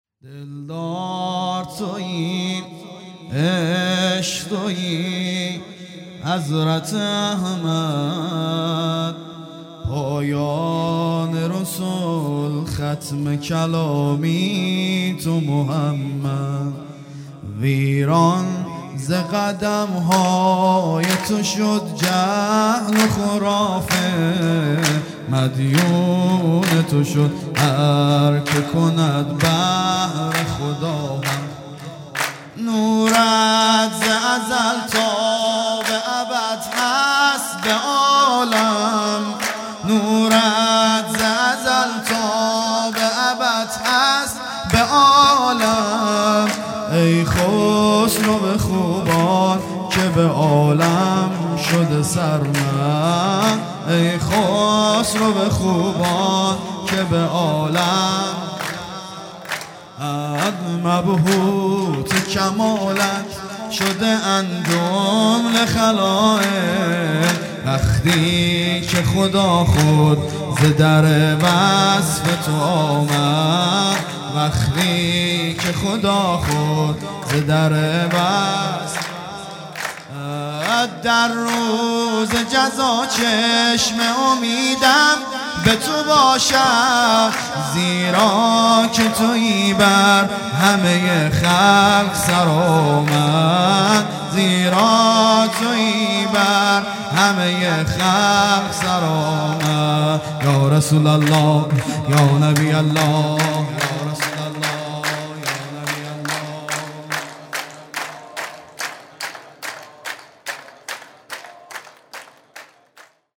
سرود
ولادت پیامبر (ص) و امام صادق (ع) | ۳ آذر ۱۳۹۷